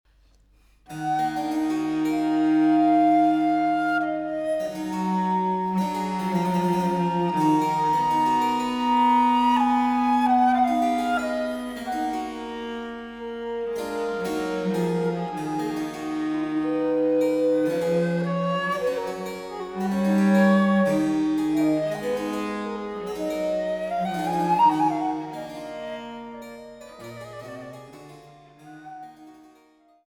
Traversflöte
Grave